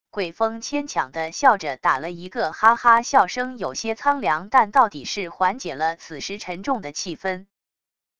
鬼风牵强的笑着 打了一个哈哈 笑声有些苍凉 但到底是缓解了此时沉重的气氛wav音频生成系统WAV Audio Player